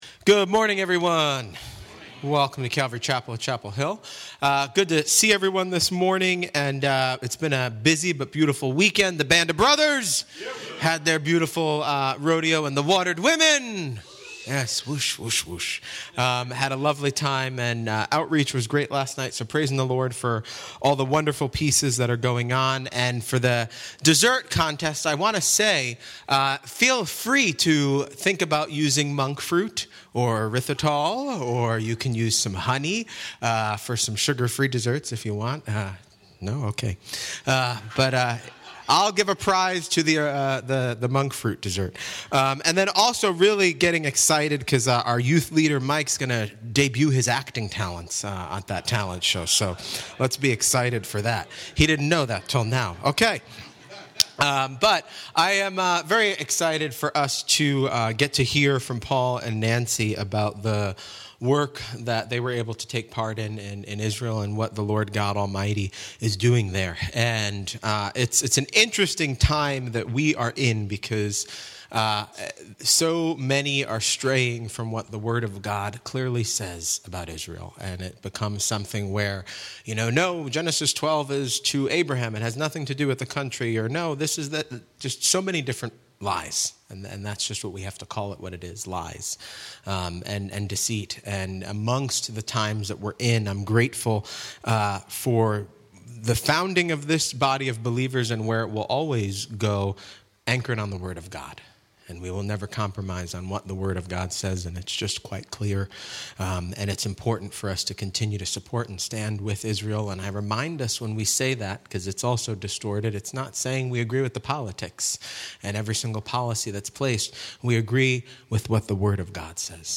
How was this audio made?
*apologies for the poor audio quality - technical difficulties*By Lighthouse Chapel … continue reading